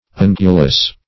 ungulous - definition of ungulous - synonyms, pronunciation, spelling from Free Dictionary Search Result for " ungulous" : The Collaborative International Dictionary of English v.0.48: Ungulous \Un"gu*lous\, a. [See Ungula .]